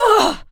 m_721_death_03.wav